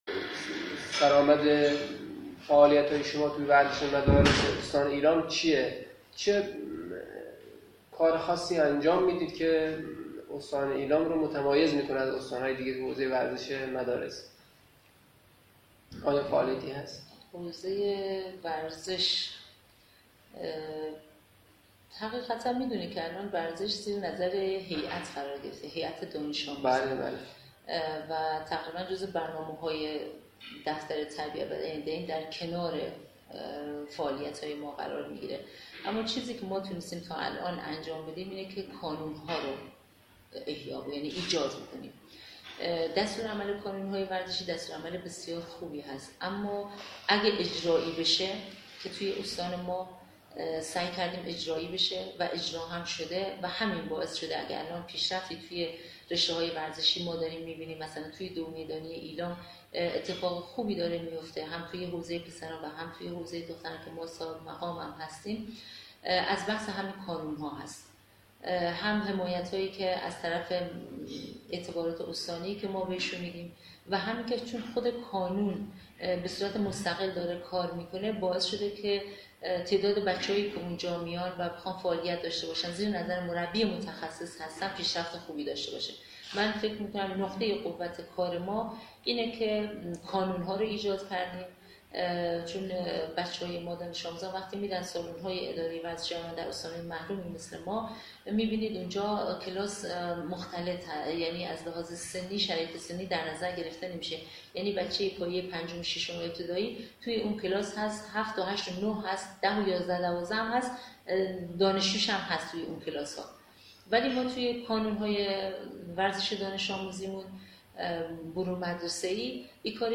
گفت و گو با معاون تربیت بدنی و سلامت استان ایلام